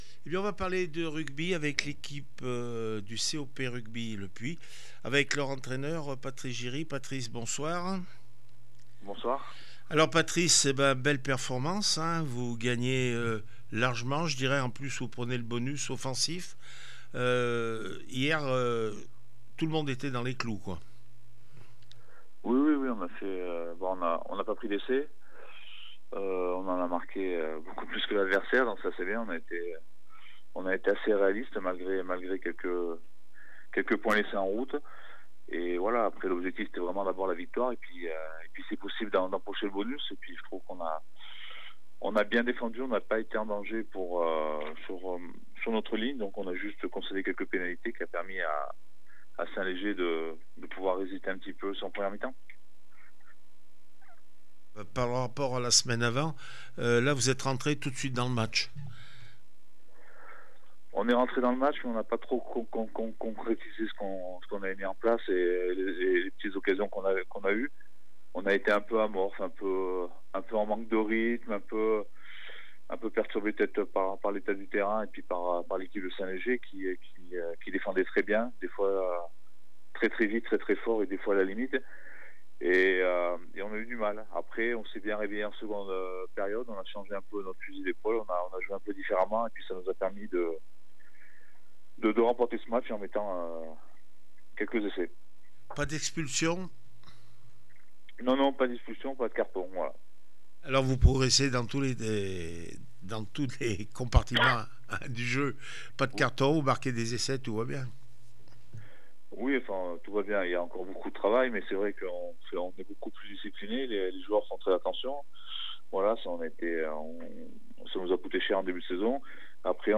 4 décembre 2025   1 - Sport, 1 - Vos interviews